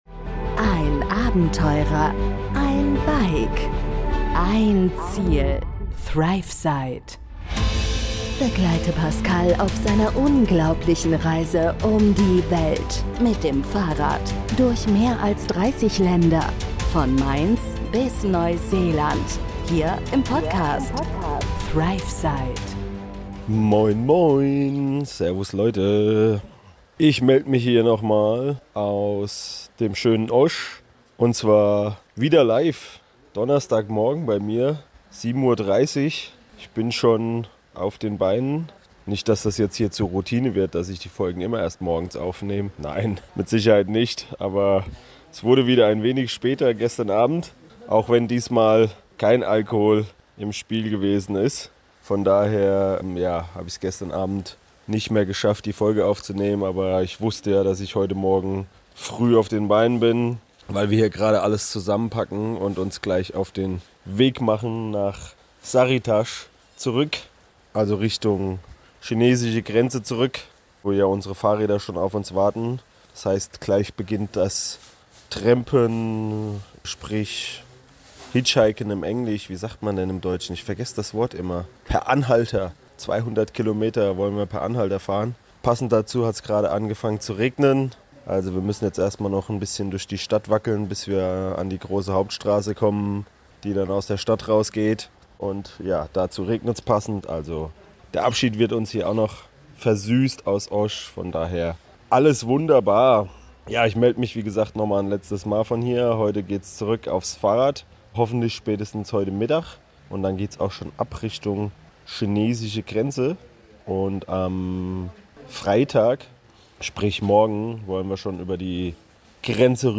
Ich melde mich live aus Osh – bei Regen und am Packen, denn gleich geht’s per Anhalter 200 km Richtung chinesische Grenze.